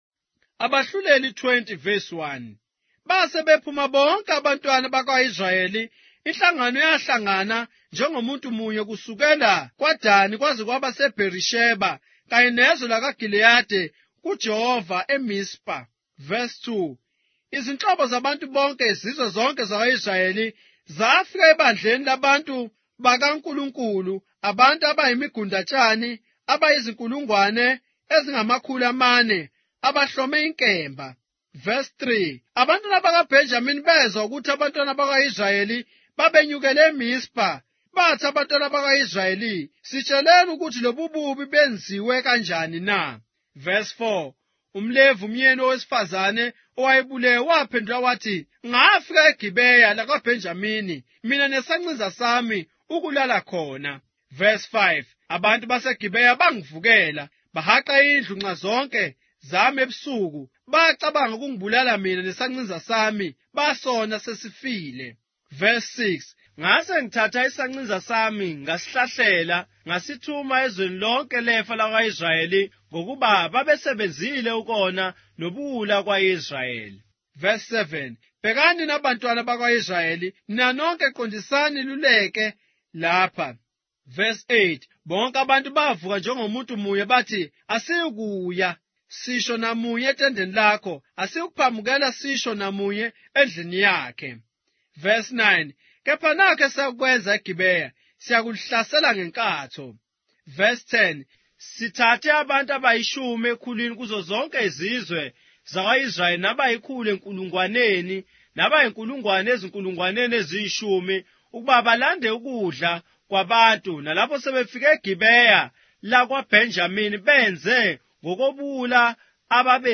Judges, chapter 20 of the Zulu Bible, with audio narration